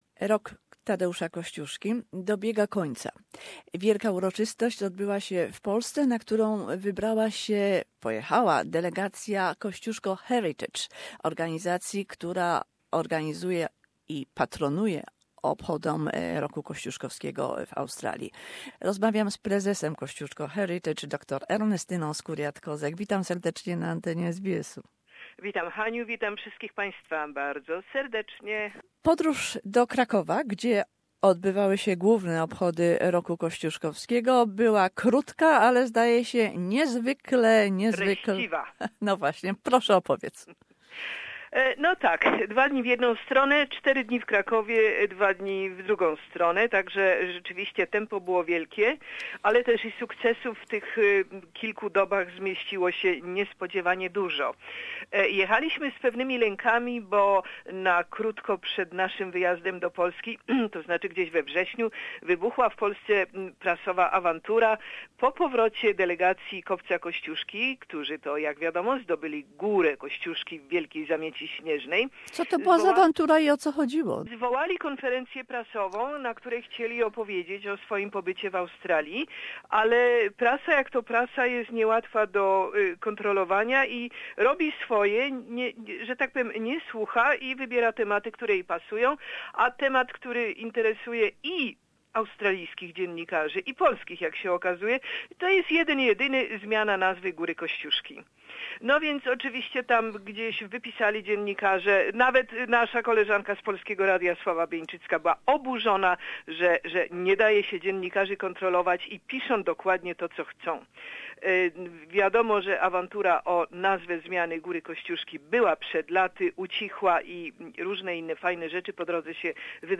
Ngariga Elders took part in Kosciuszko Bicentenary celebrations in Cracow. Interview